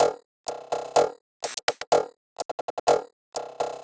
Weird Glitch 02.wav